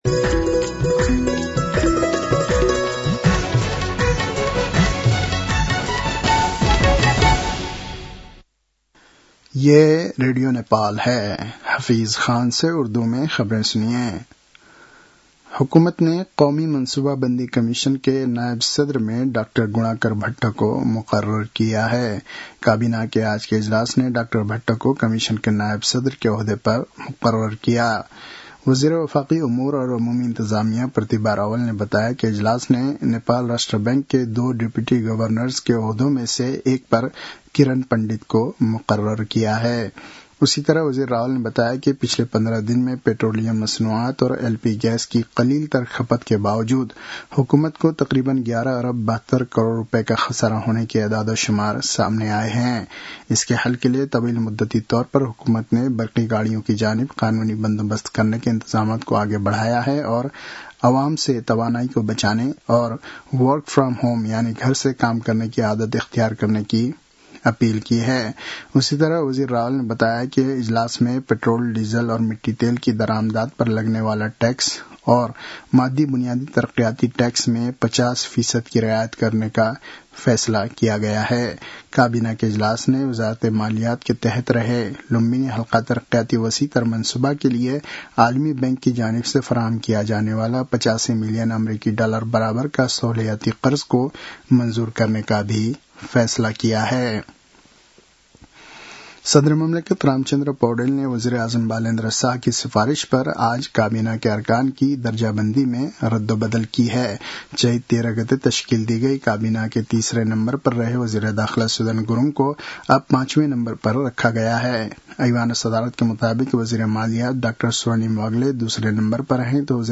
उर्दु भाषामा समाचार : २४ चैत , २०८२